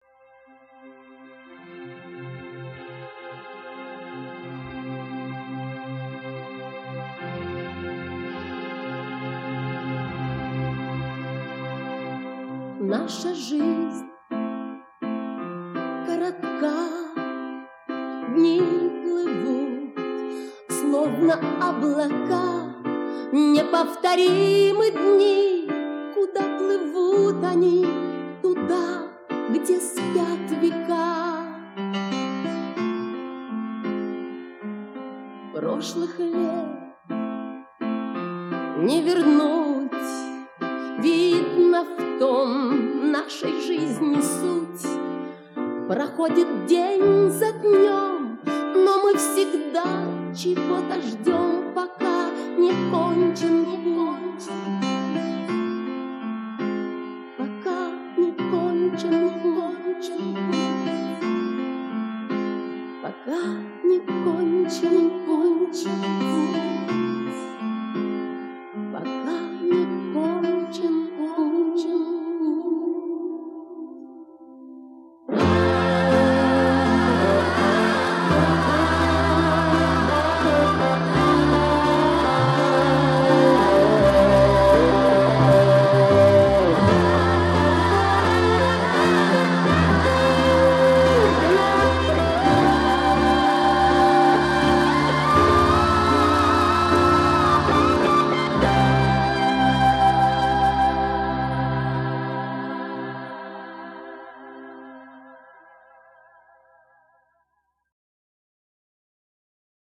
Жанр: Electronic, Rock, Pop
Стиль: Music Hall, Pop Rock, Synth-pop